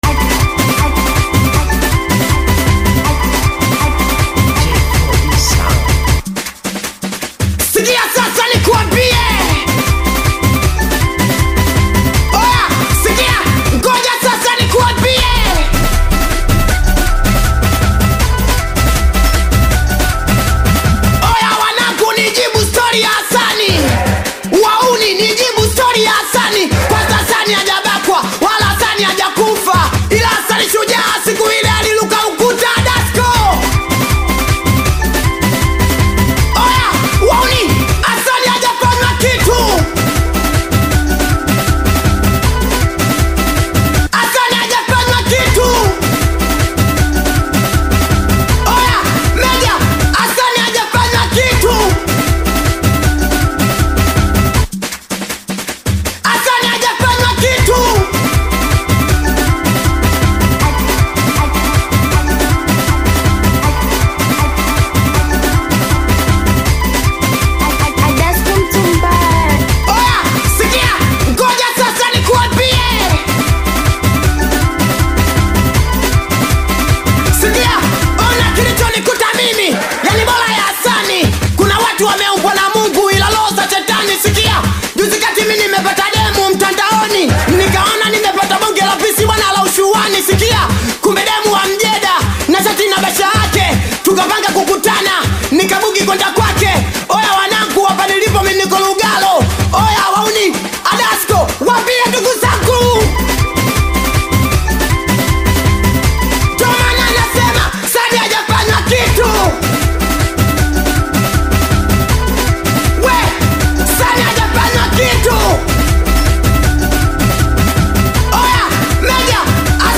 Tanzanian Bongo Flava singeli
Singeli